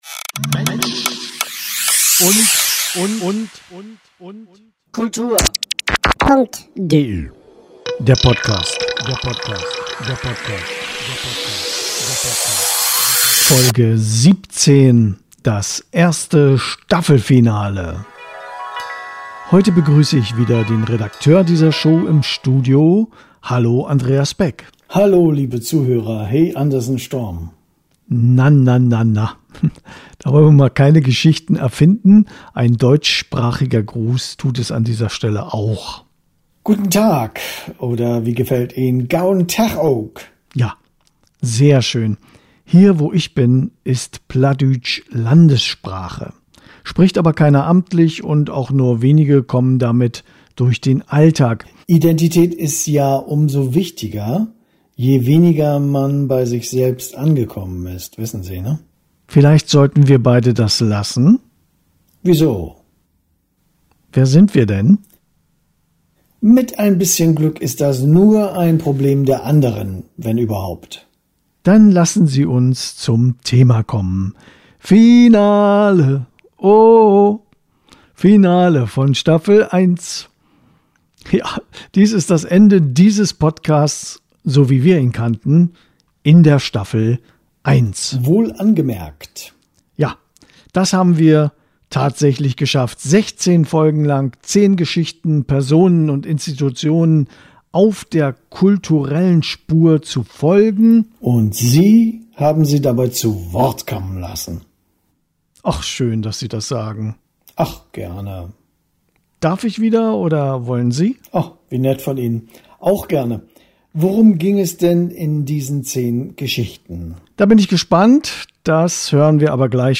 Es kommen alle Protagonisten der Folgen zur Wort.
Mit Humor, Liebe und Unterhaltungswert.